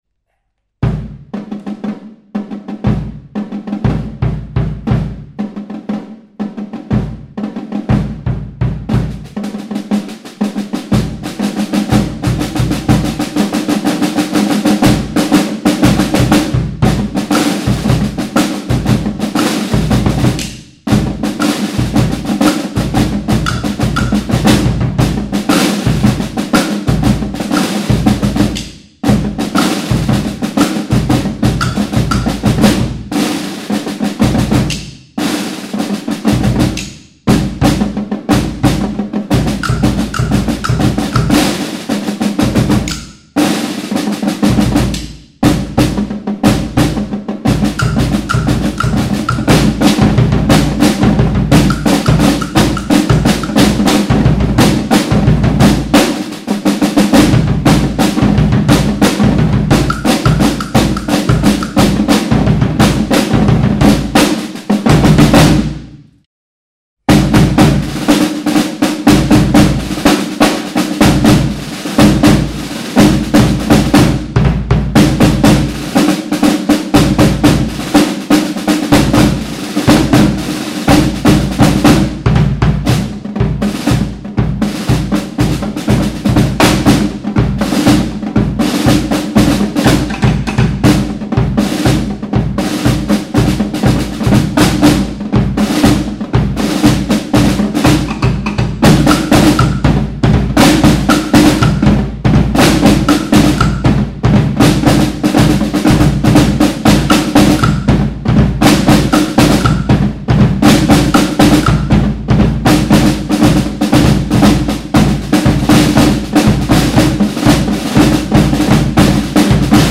stimmlose Percussion.